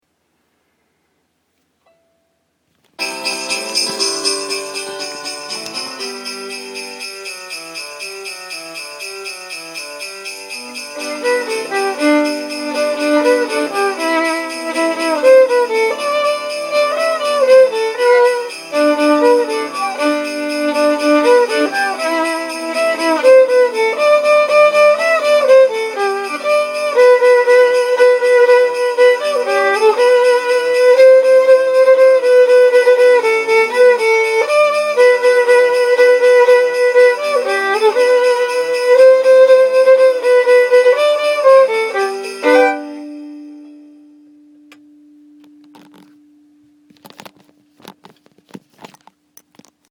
ちなみに去年のﾚｽﾄﾗﾝ余興前に録音したのもひょっこり出てきたので、ついでにｱｯﾌﾟ。
Pf他…自作PC音源
ジングルベルとかの鈴っぽい音もPCで入れるんですか？